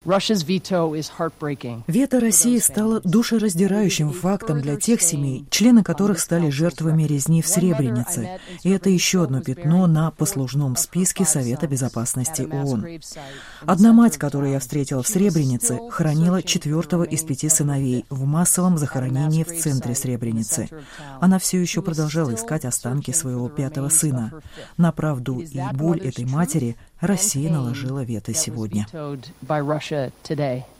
Постпред США при ООН Саманта Пауэр о вето России на резолюцию Совбеза о Сребренице